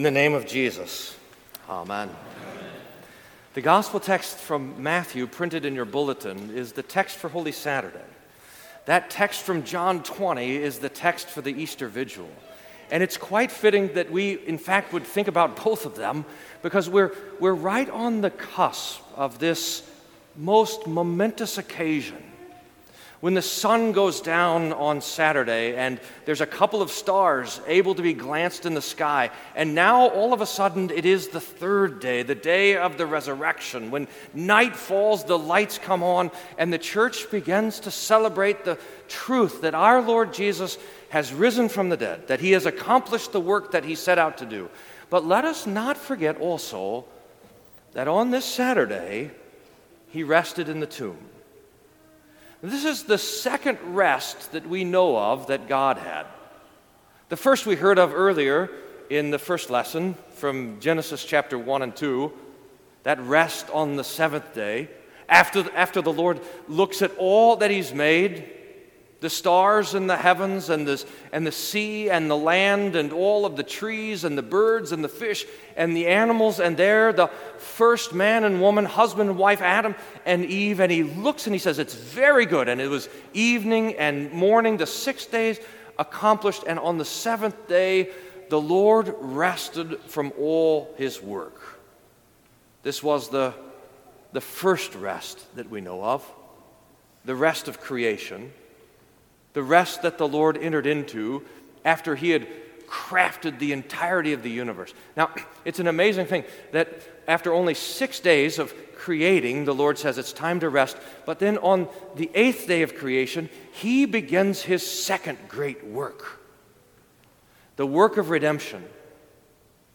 Sermon for Easter Vigil